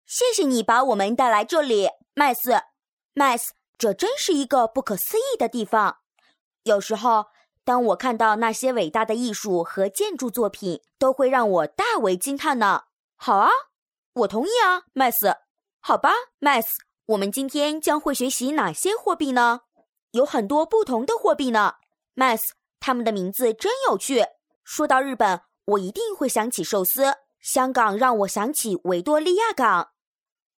标签： 活力
配音风格： 活力 甜美 亲切 优雅 年轻 轻快 可爱 激情 配音语言： 普通话